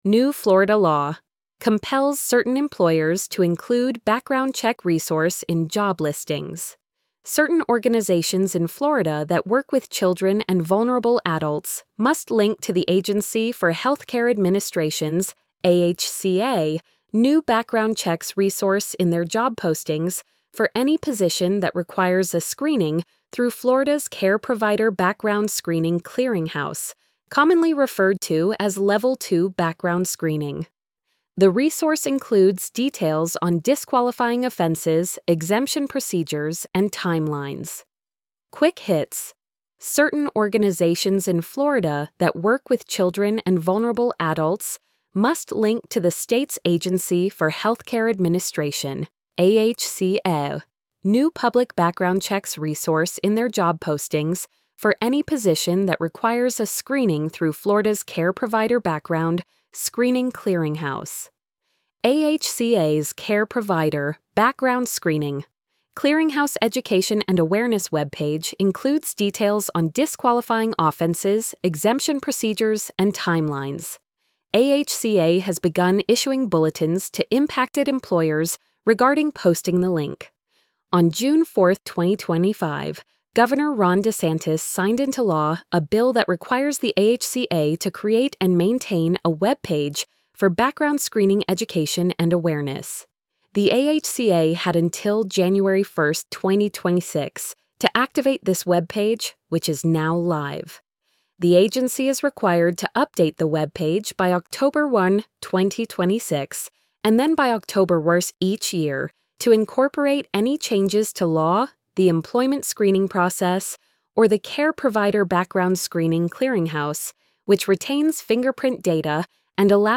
new-florida-law-compels-certain-employers-to-include-background-check-resource-in-job-listings-tts.mp3